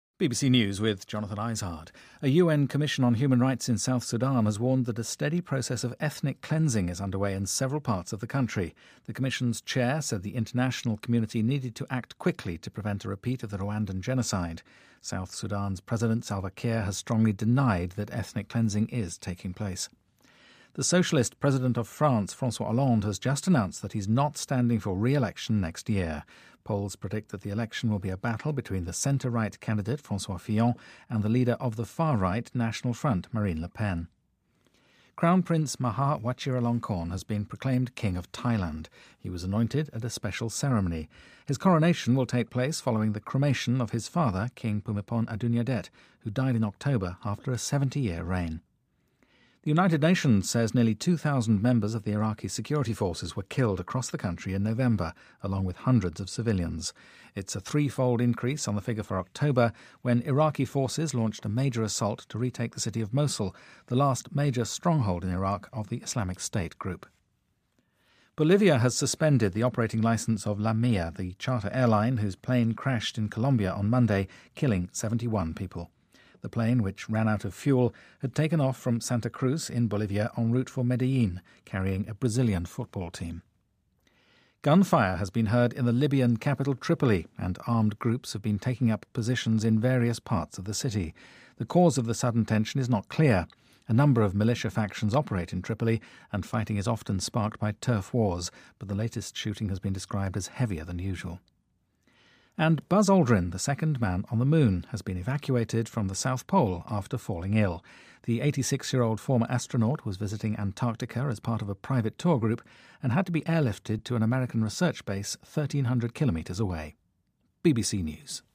BBC news,泰国王储哇集拉隆功正式即位